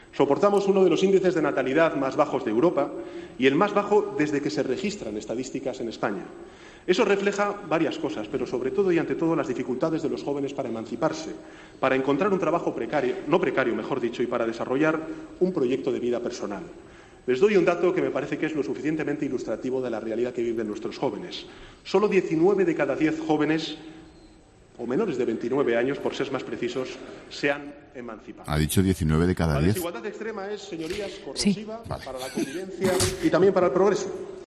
Sánchez ha pronunciado un largo discurso en el que no ha podido evitar trabarse, confundir términos y no ser demasiado claro